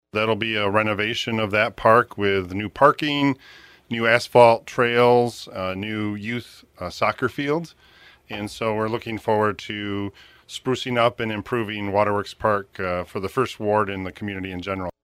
City Manager Keith Baker said during a City Council meeting last March there will be many improvements.